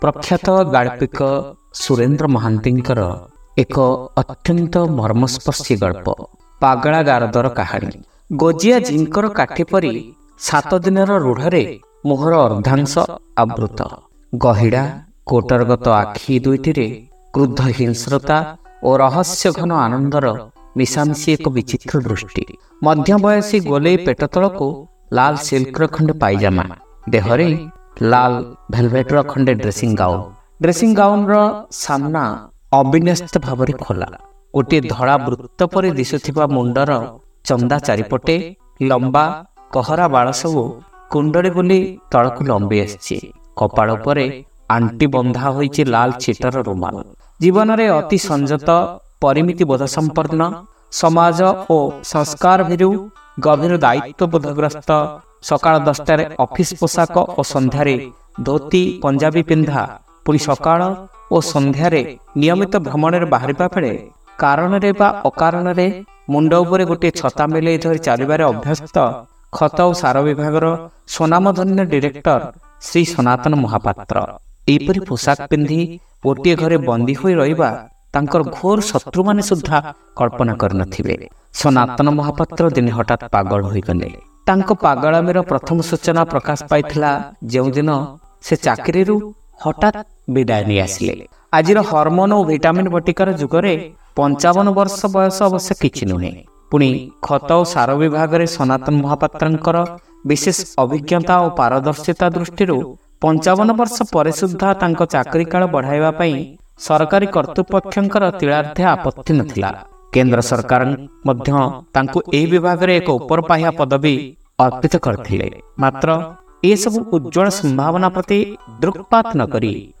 ଶ୍ରାବ୍ୟ ଗଳ୍ପ : ପାଗଳାଗାରଦର କାହାଣୀ